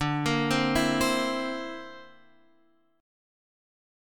D7sus2#5 chord